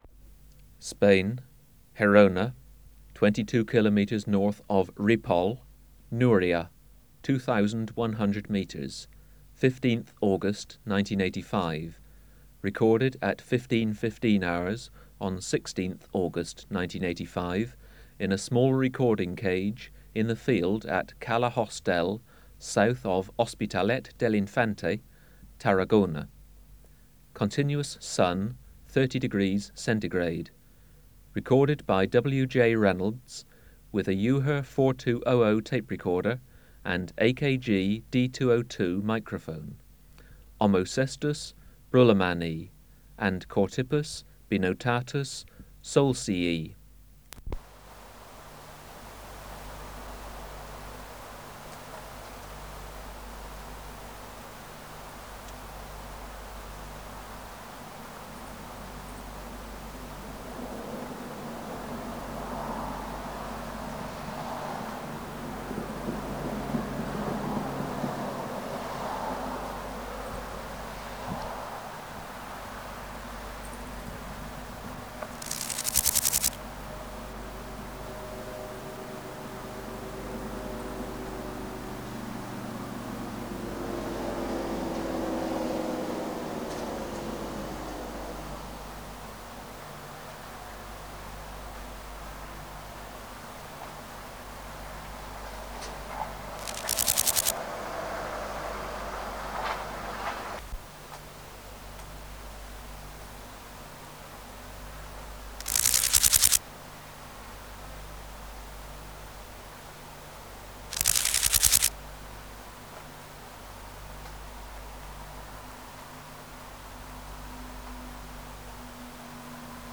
Chorthippus (Glyptobothrus) binotatus
Omocestus broelemanni